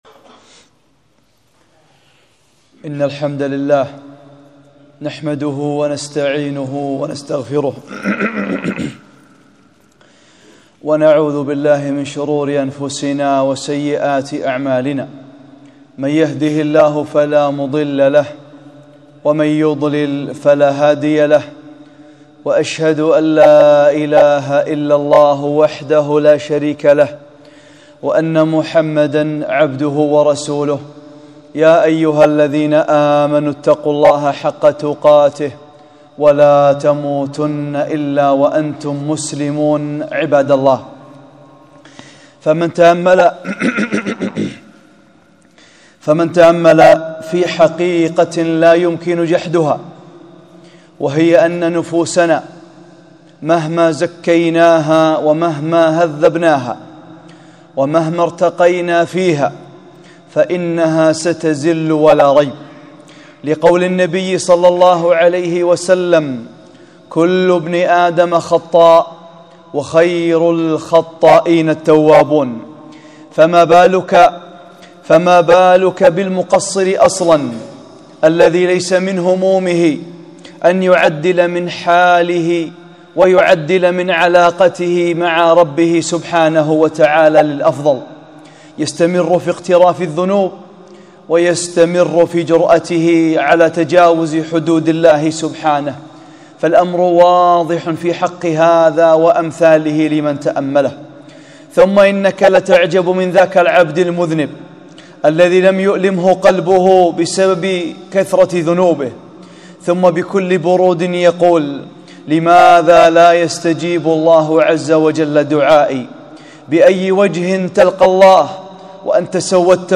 خطبة - حقيقة التوبة